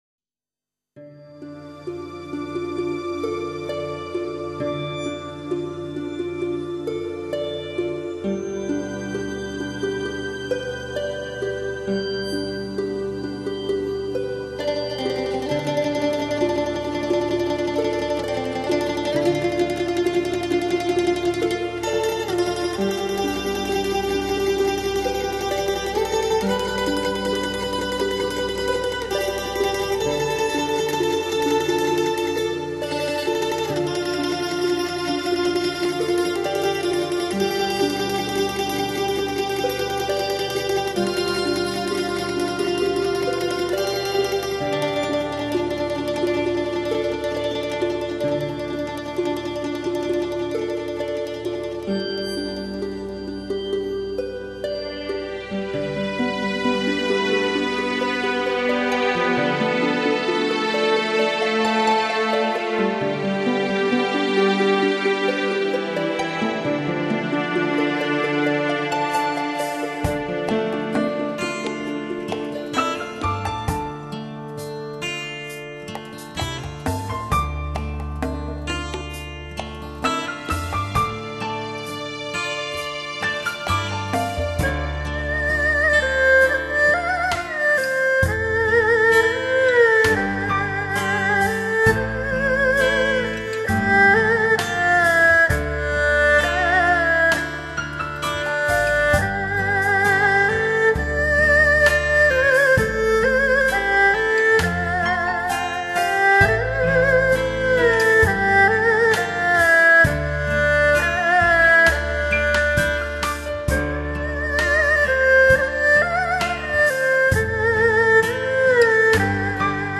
音色双绝 意境撩人 顶级发烧乐之瑰宝
青海民歌
二胡
吉他
曼陀铃